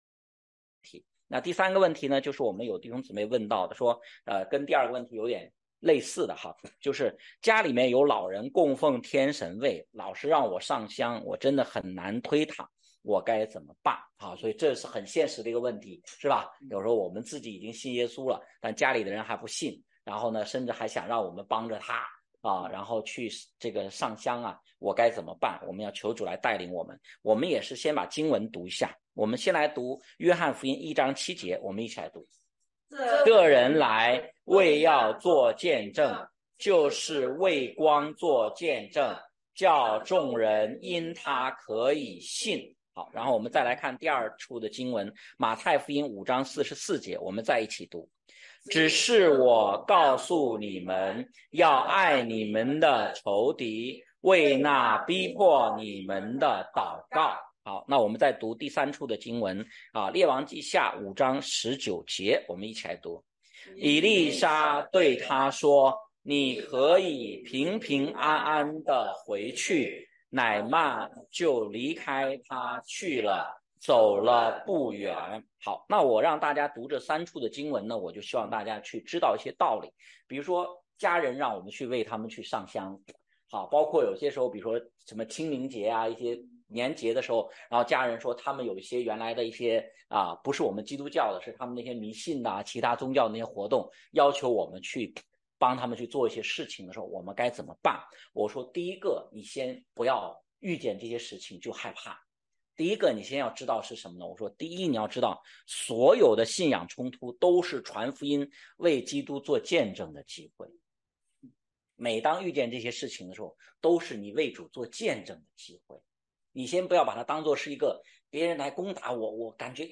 问题解答录音